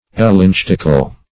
Elenchtic \E*lench"tic\, Elenchtical \E*lench"tic*al\, a.
elenchtical.mp3